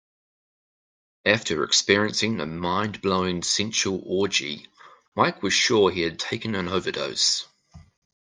Read more Noun Name Frequency A2 Pronounced as (IPA) /maɪk/ Etymology Shortened from Michael.